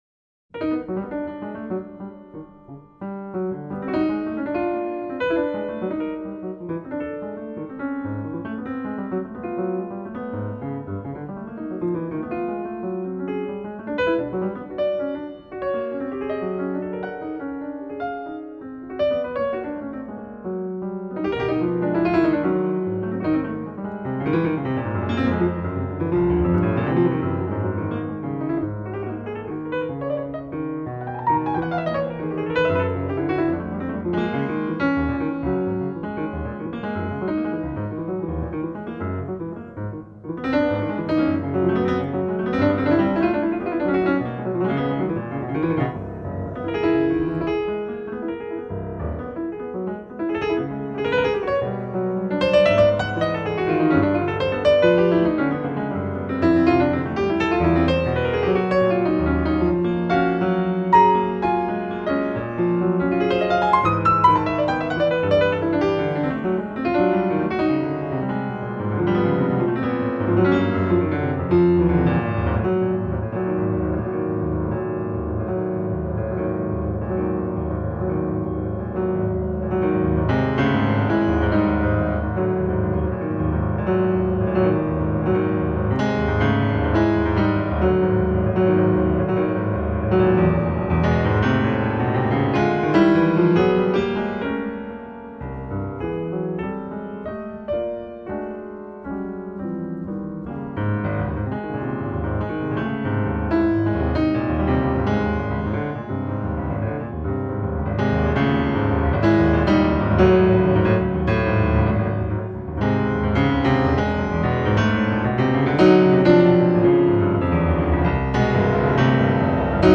Modern / Solo Piano.
loose jazzy feel
Other pieces have an electrified punch à la Randy Weston.